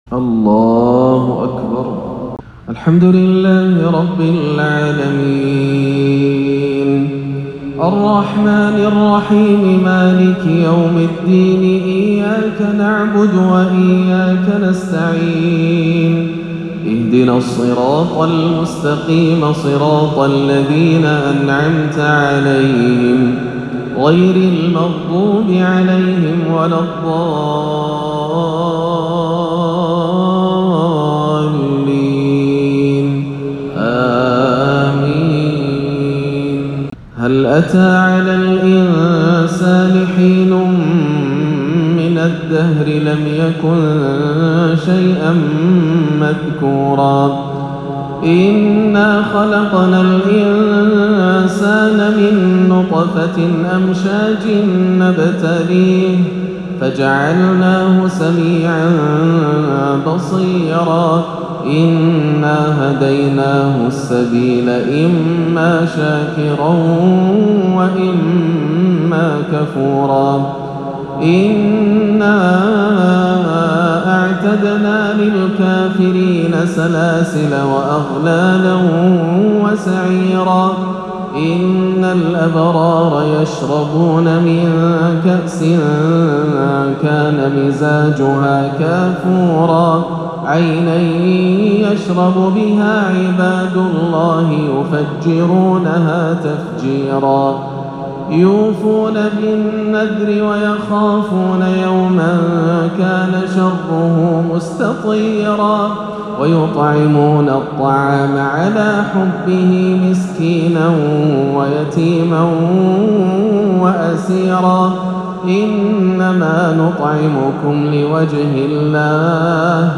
ترتيل بديع متنوع لسورة الإنسان كاملة - عشاء الثلاثاء 3-11 > عام 1439 > الفروض - تلاوات ياسر الدوسري